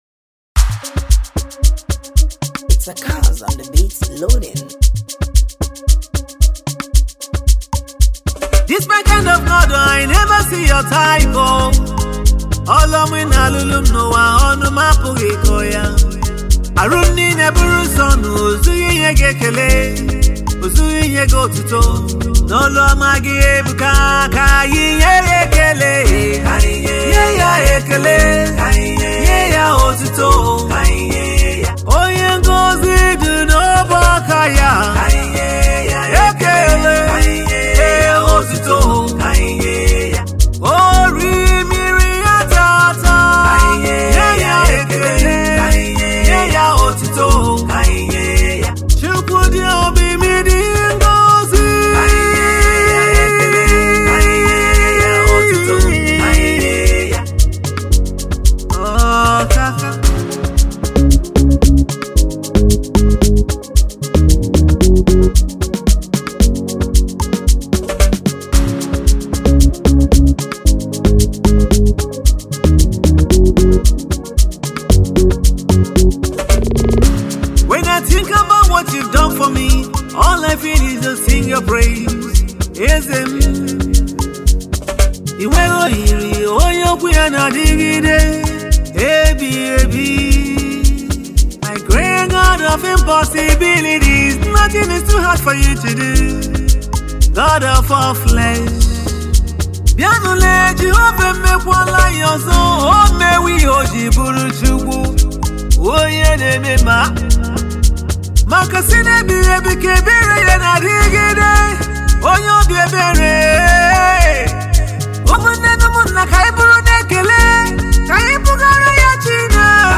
Nigerian gospel music minister and songwriter
an Amapiano beat laced song